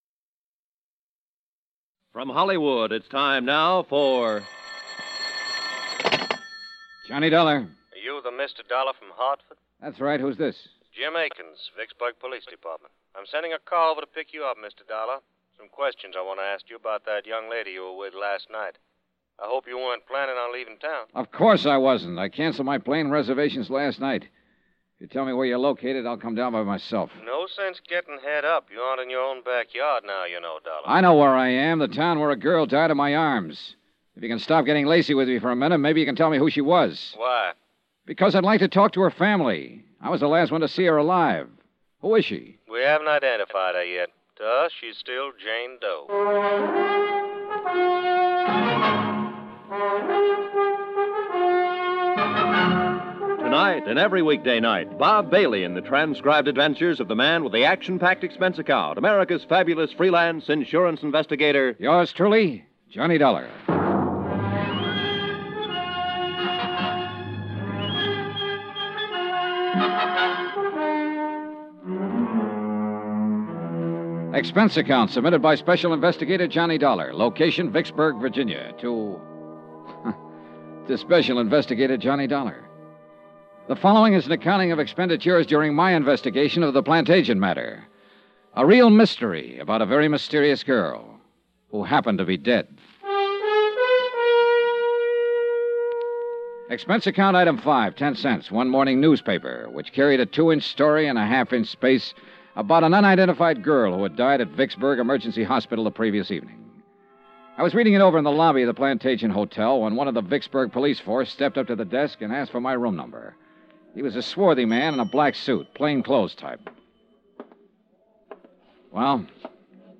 Yours Truly, Johnny Dollar Radio Program, Starring Bob Bailey